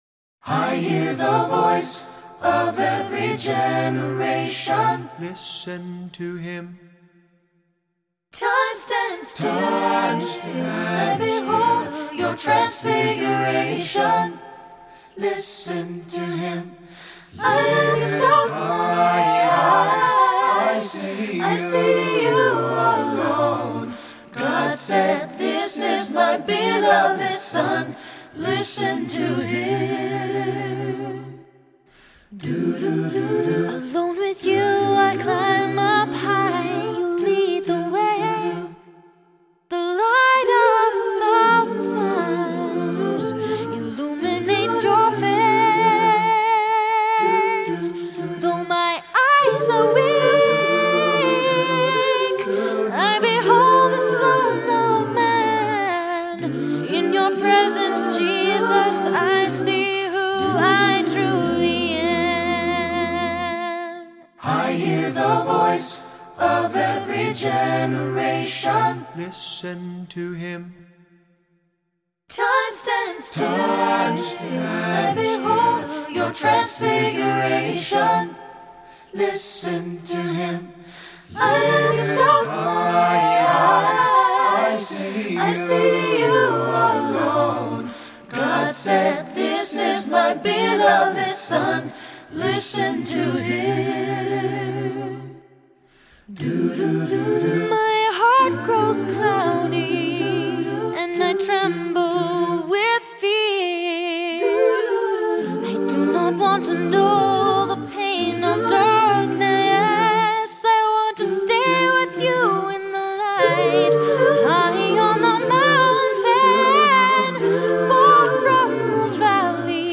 Voicing: SATB; Solo; a cappella